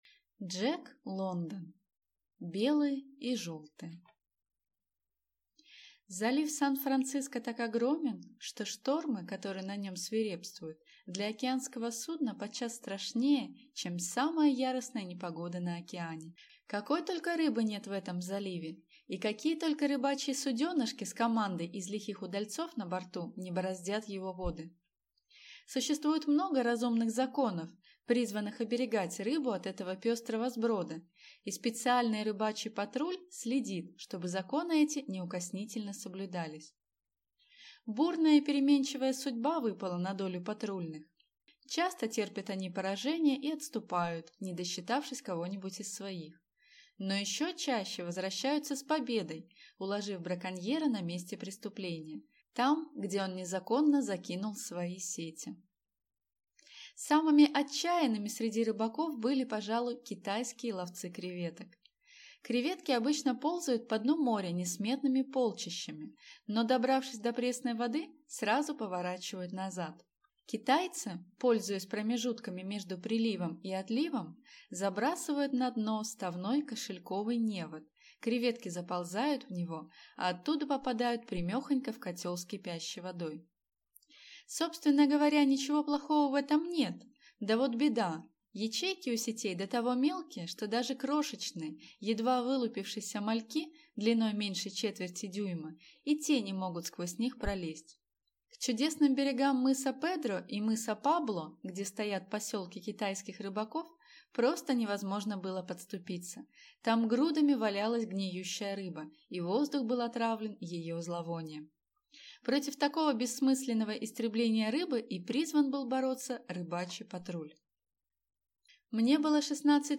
Аудиокнига Белые и желтые | Библиотека аудиокниг